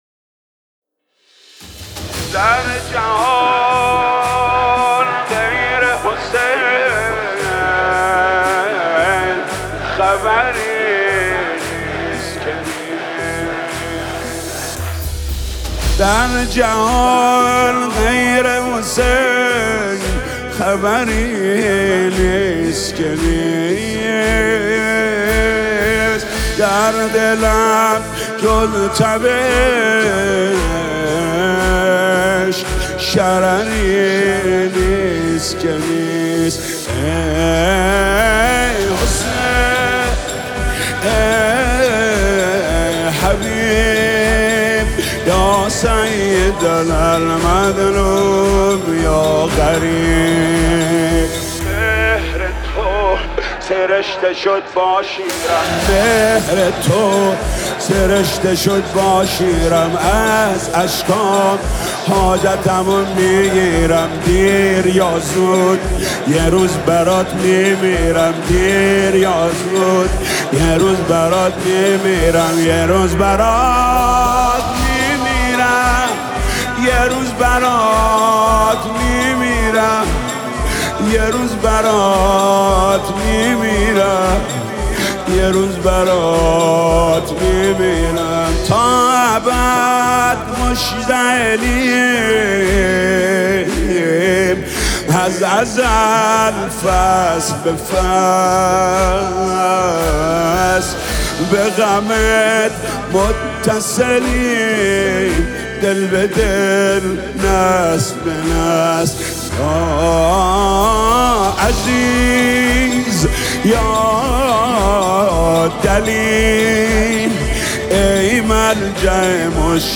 نماهنگ